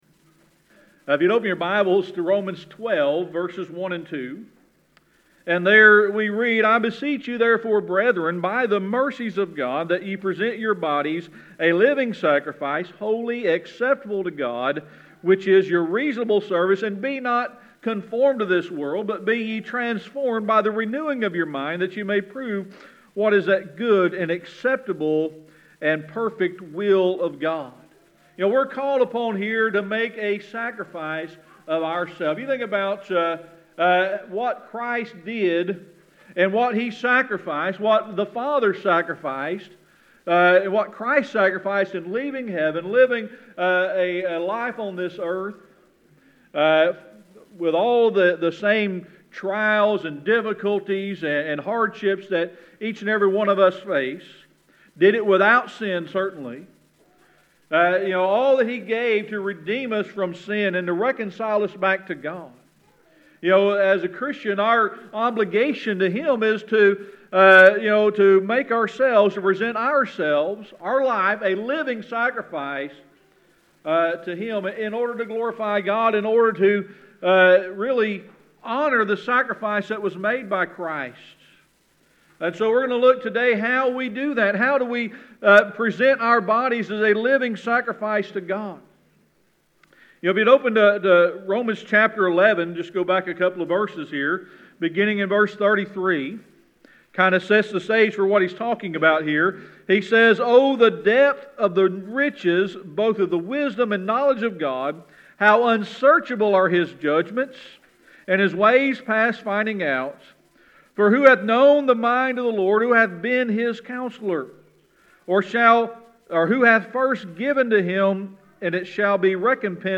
Romans 12:1-2 Service Type: Sunday Morning Worship Open your Bibles to Romans 12:1 and 2.